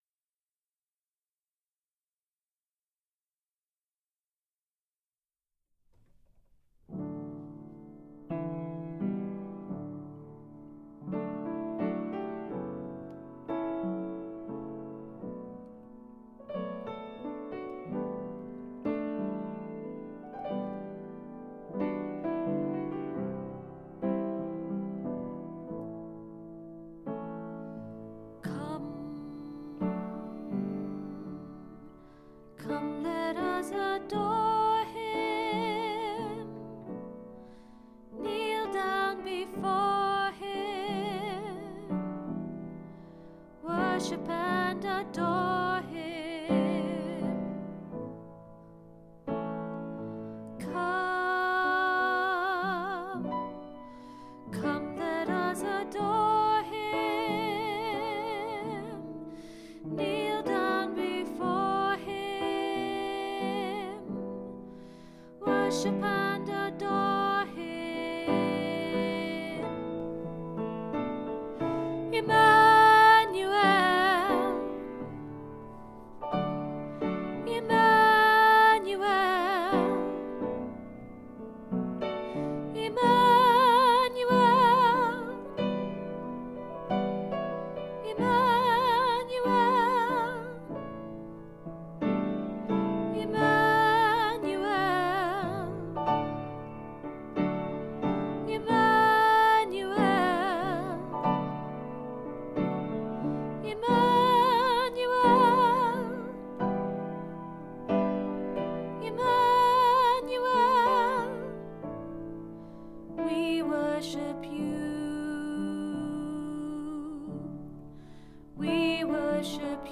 Emmanuel Alto - Three Valleys Gospel Choir
Emmanuel Alto